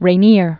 (rā-nîr, rĕ-, rə-, rĕ-nyā) 1923-2005.